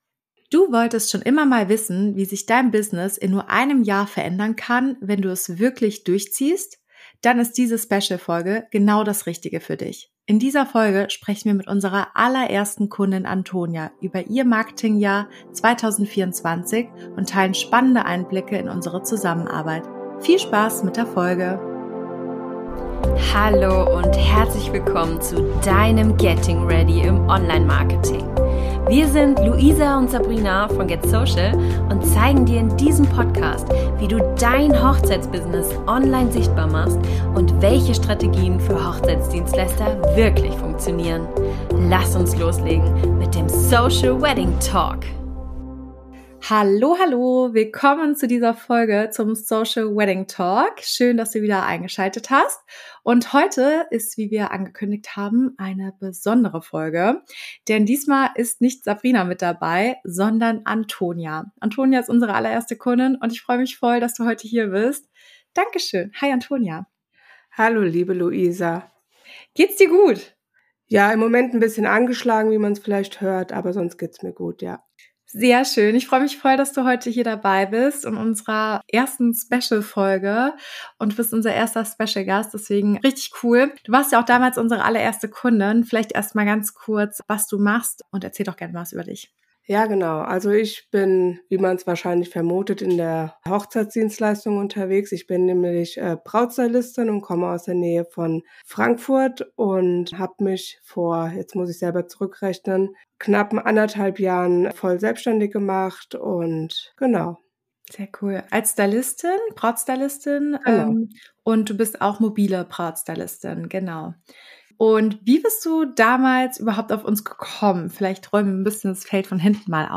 #20 - Interview